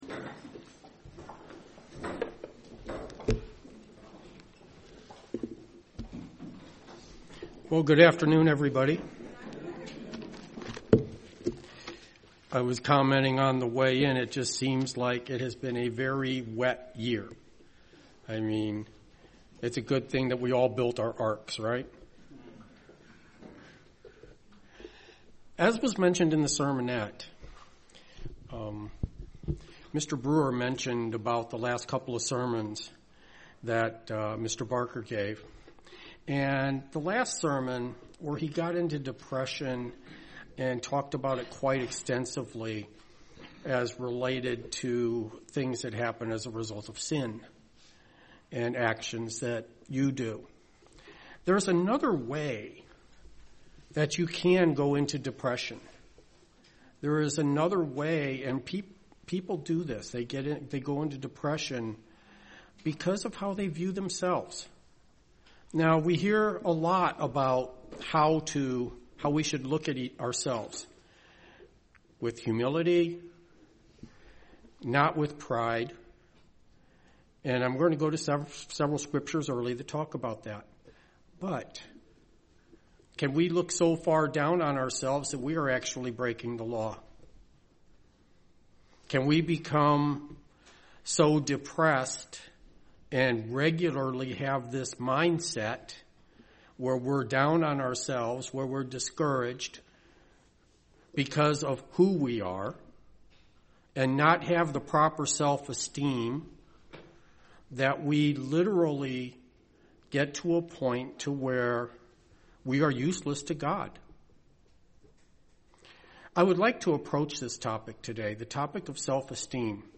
Given in Dayton, OH